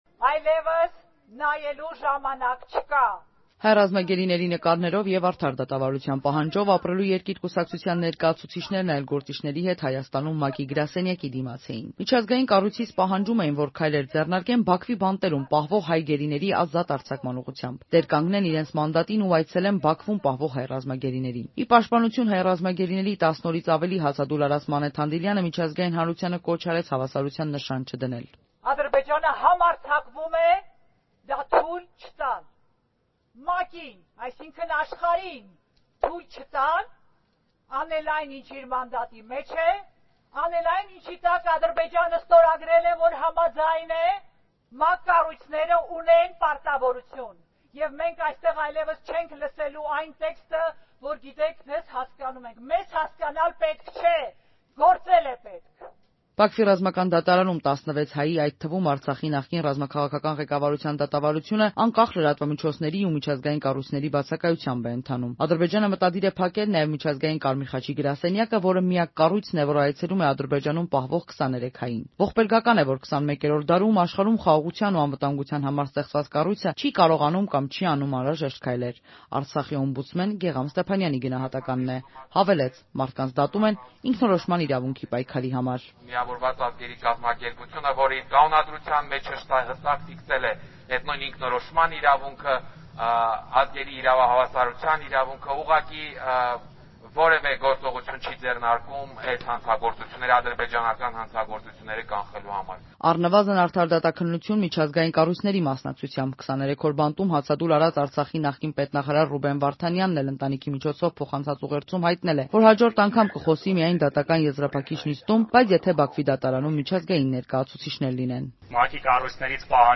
Ակցիա ՄԱԿ-ի գրասենյակի դիմաց՝ հայ գերիների ազատ արձակման ուղղությամբ քայլեր ձեռնարկելու պահանջով
Ռեպորտաժներ